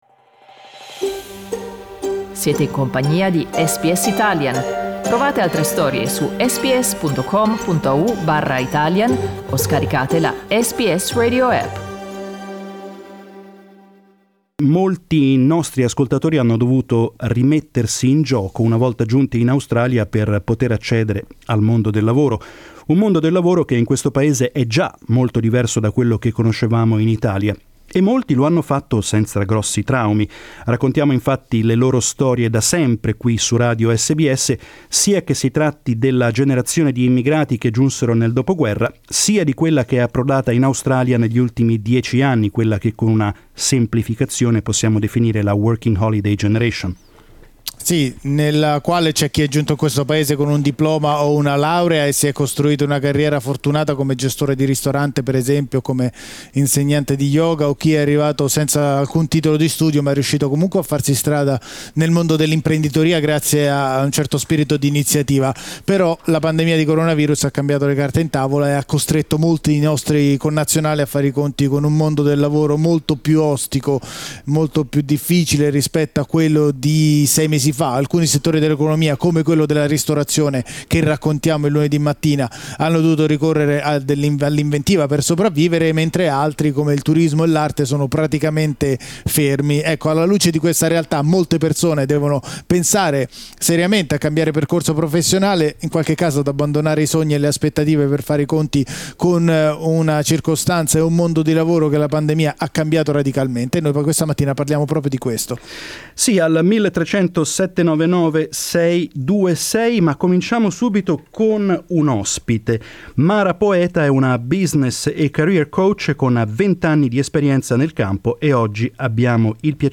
Ne abbiamo parlato questa mattina in diretta con i nostri ascoltatori